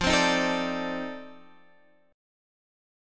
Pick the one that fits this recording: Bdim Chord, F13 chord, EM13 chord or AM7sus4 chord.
F13 chord